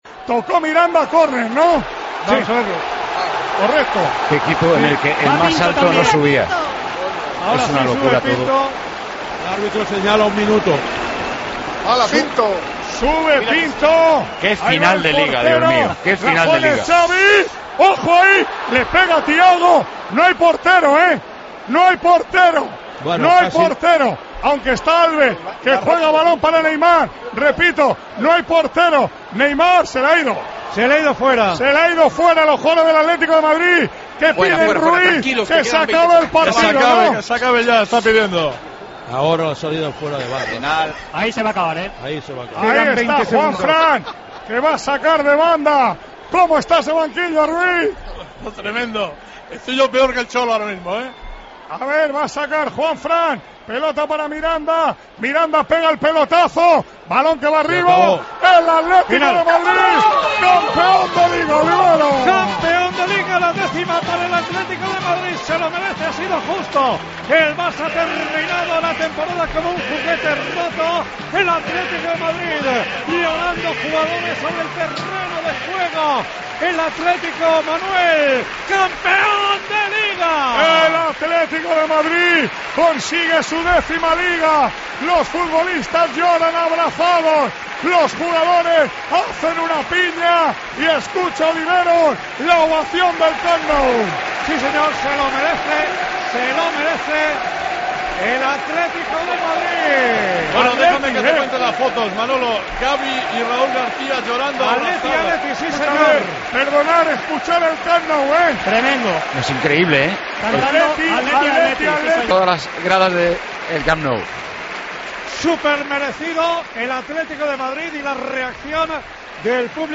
Redacción digital Madrid - Publicado el 17 may 2014, 20:59 - Actualizado 14 mar 2023, 07:33 1 min lectura Descargar Facebook Twitter Whatsapp Telegram Enviar por email Copiar enlace Escucha el final del partido entre el Barcelona y el Atlético de Madrid y la celebración de los jugadores rojiblancos, tras lograr el título de Liga en el Camp Nou.